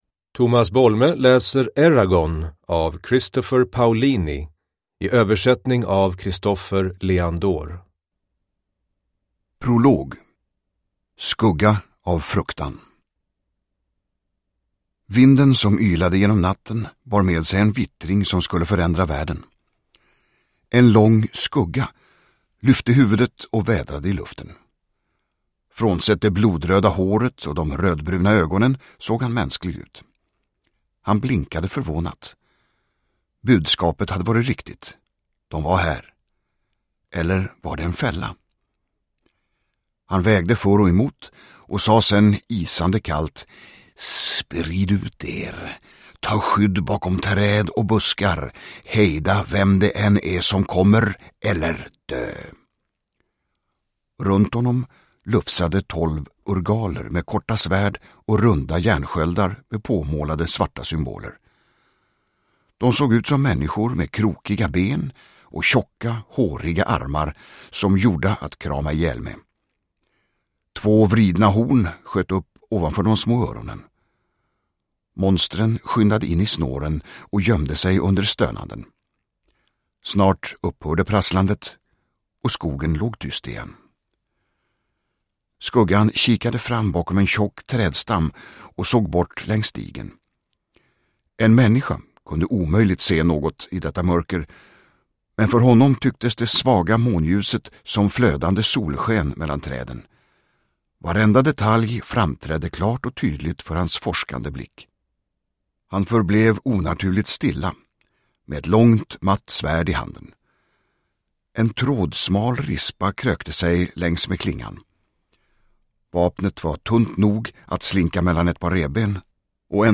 Eragon – Ljudbok
Uppläsare: Tomas Bolme